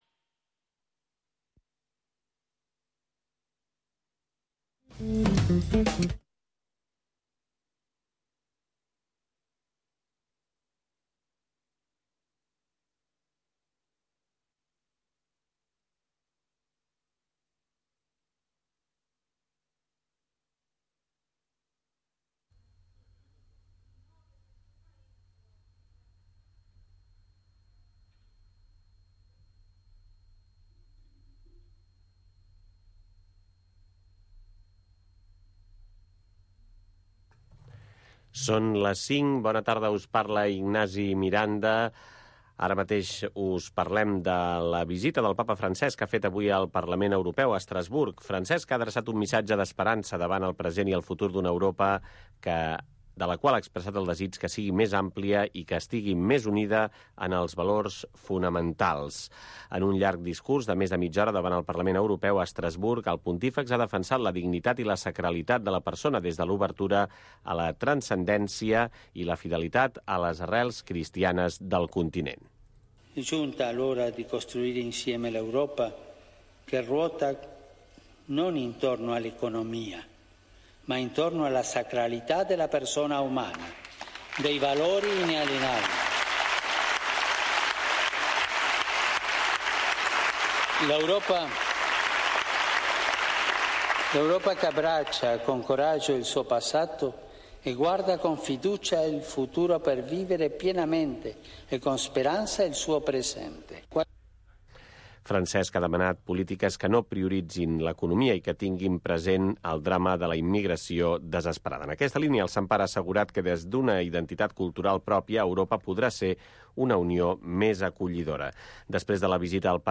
Estem amb tu. Magazín cultural de tarda.